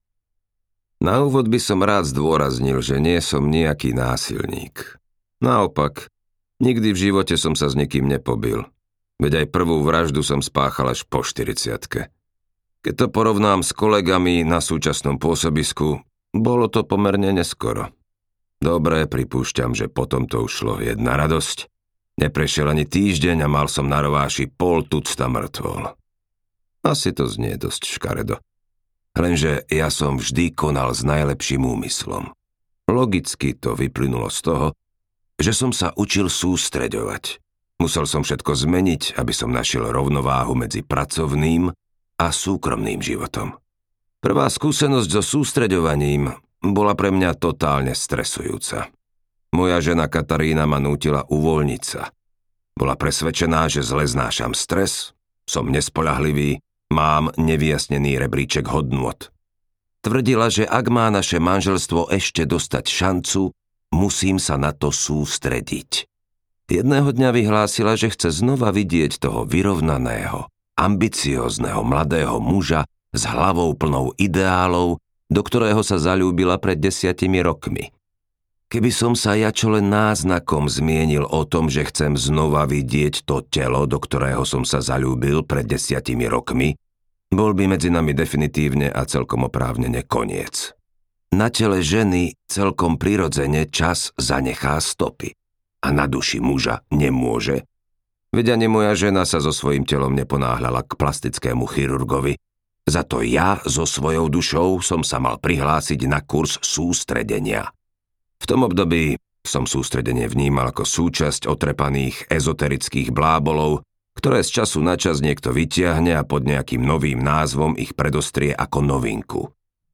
Audio knihaSústreď sa na vraždu
Ukázka z knihy